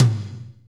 TOM F S M0XL.wav